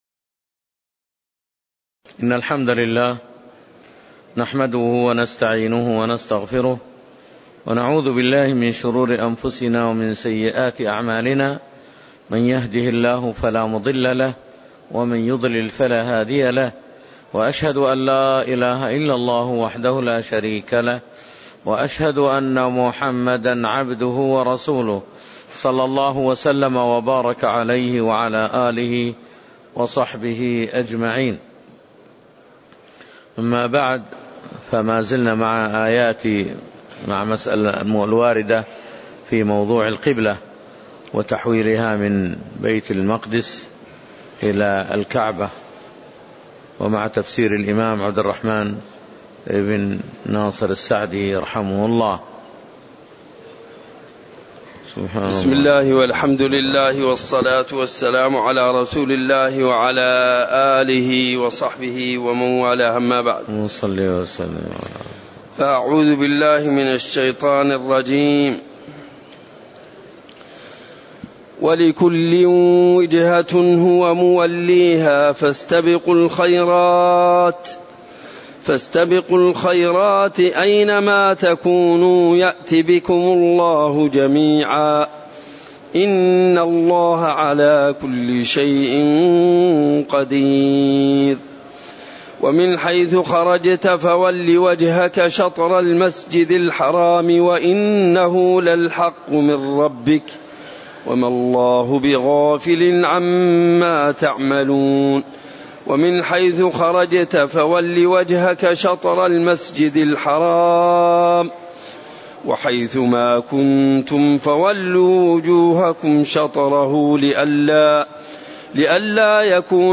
تاريخ النشر ١٩ جمادى الآخرة ١٤٣٨ هـ المكان: المسجد النبوي الشيخ